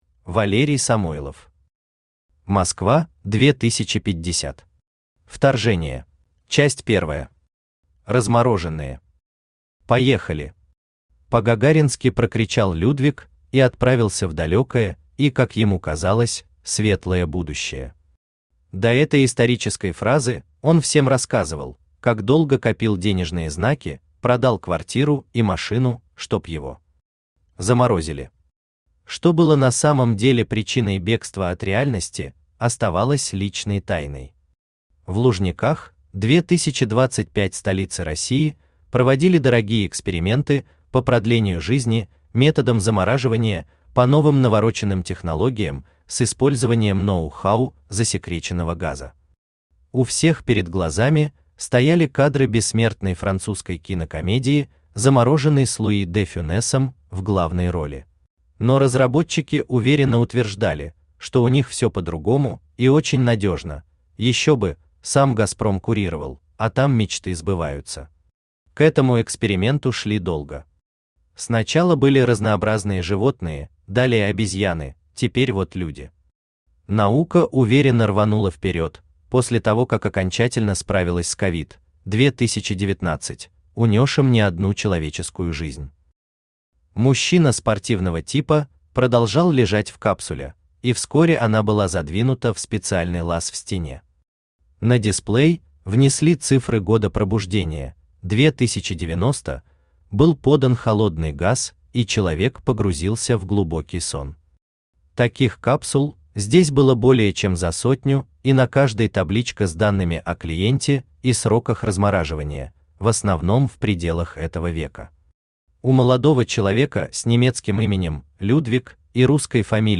Аудиокнига Москва-2050. Вторжение | Библиотека аудиокниг
Вторжение Автор Валерий Александрович Самойлов Читает аудиокнигу Авточтец ЛитРес.